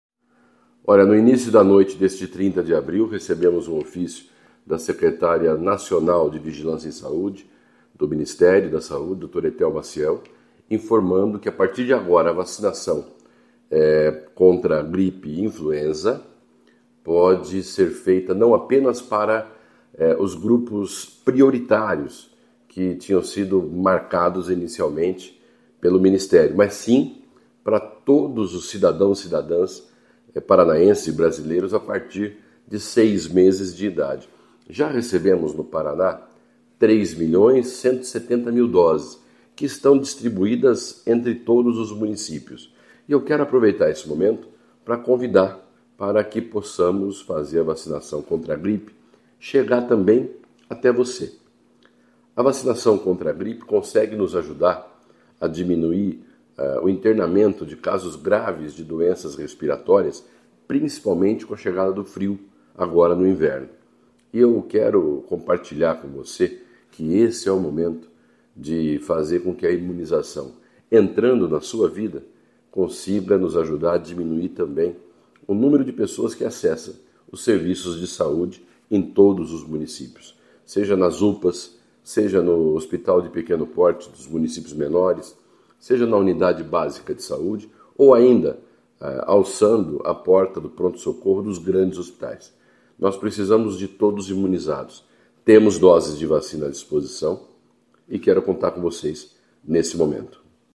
Sonora do secretário da Saúde, Beto Preto, sobre a expansão da oferta da vacina contra a gripe para todas as pessoas com mais de seis meses de idade